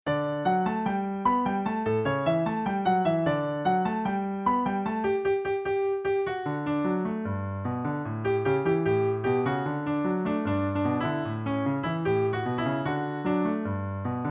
Traditional Spanish Song Lyrics and Sound Clip